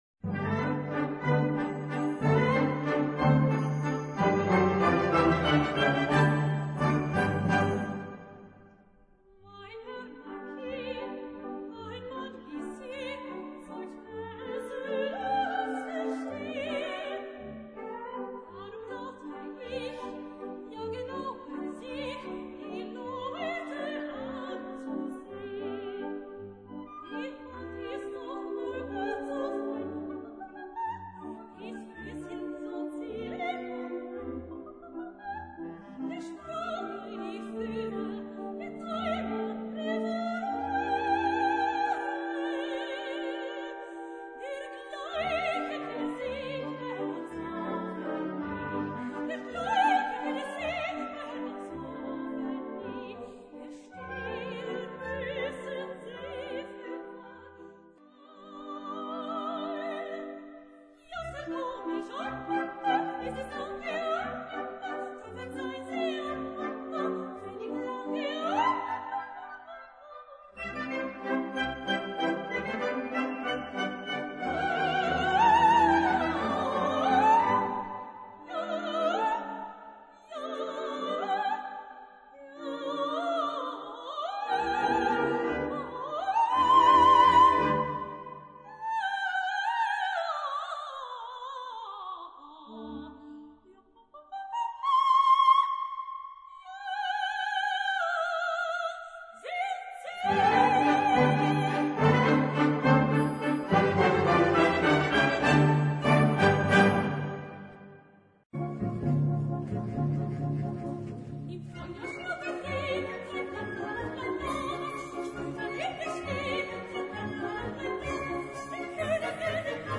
Gattung: Solo für Sopran und Blasorchester
Besetzung: Blasorchester
Zwei Couplets aus der Operette.